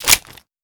chamber_out.wav